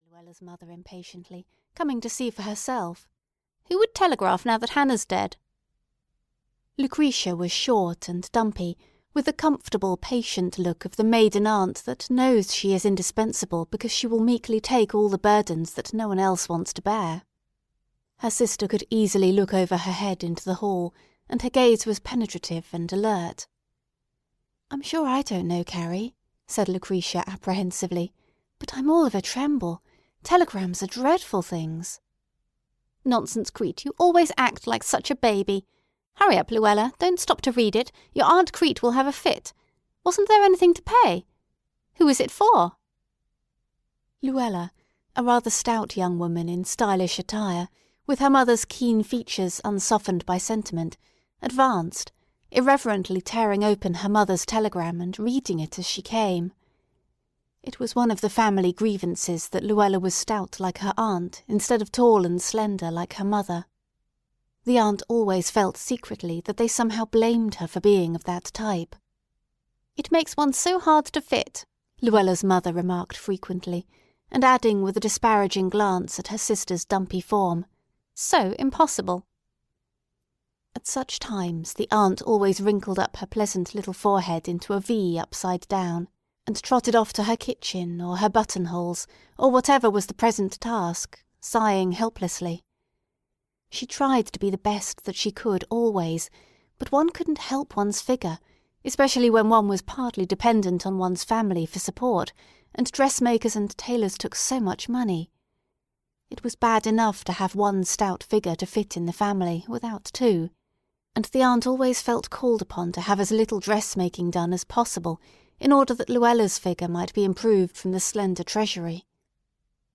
Aunt Crete's Emancipation (EN) audiokniha
Ukázka z knihy